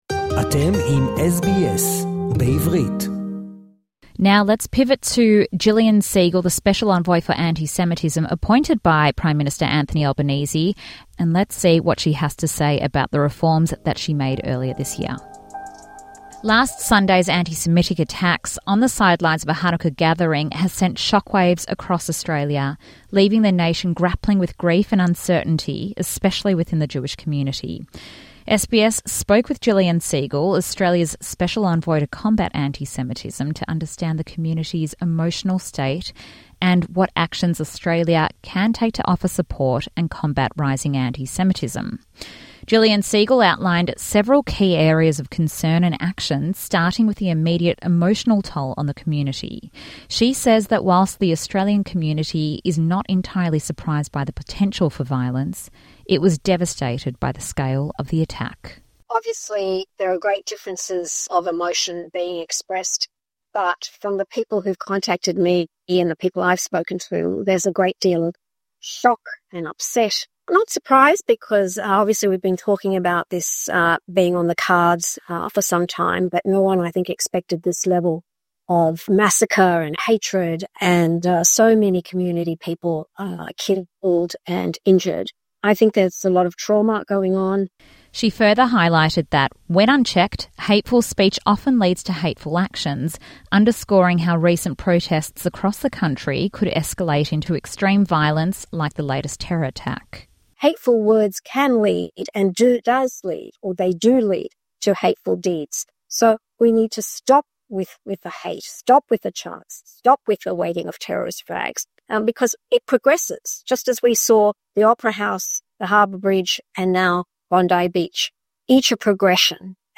SBS sits down with Special Envoy for Antisemitism, Jillian Segal, who outlines the reforms that she made last year. In this interview, Segal defines exactly what differentiates antisemitism to other forms of hate speech, and highlights a national multi-year strategy in order to combat the issue.